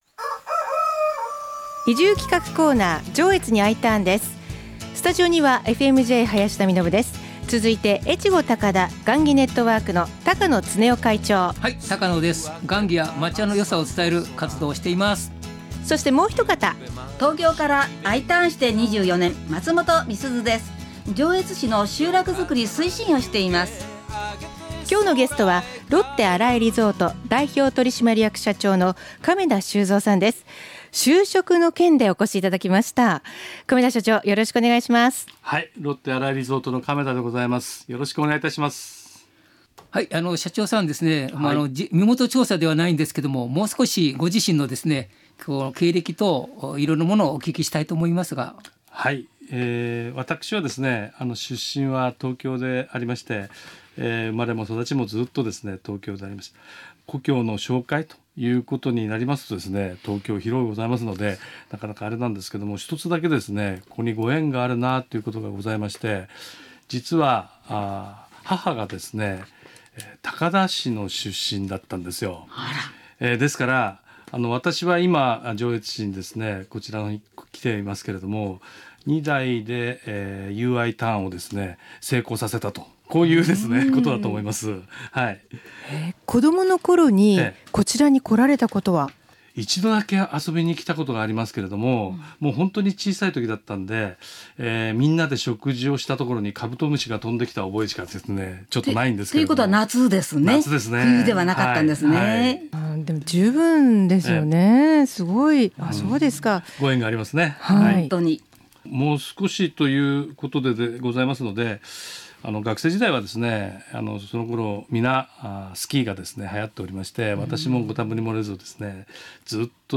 FM-Jのスタジオから移住をお誘いするコーナー。